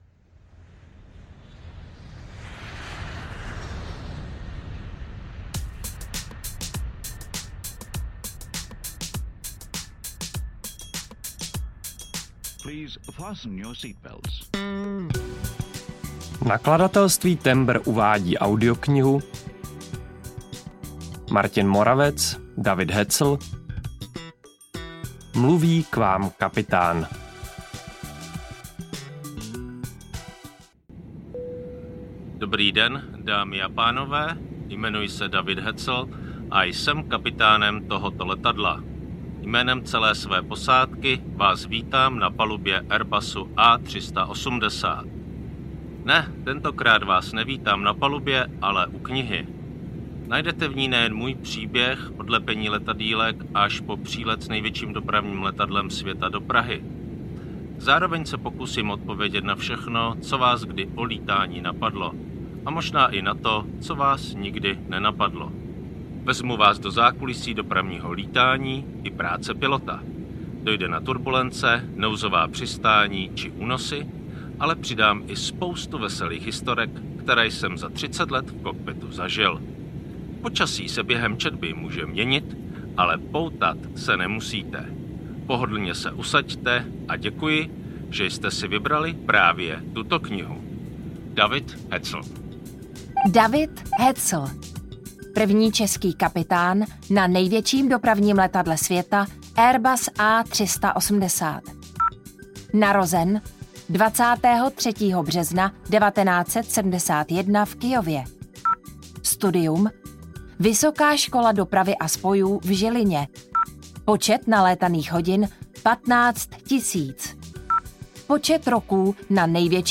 Ukázka z knihy
• InterpretJan Šťastný